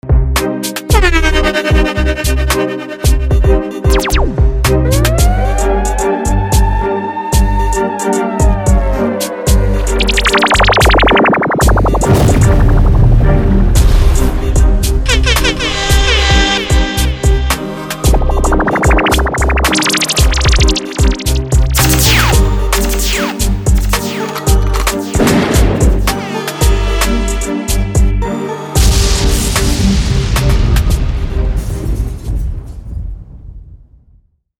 Essential Reggae Dancehall SFX (vol 1)
3 x Airhorn
3 x Laser
3 x Impact
3 x Bubblin
3 x Siren
1 x Gunshot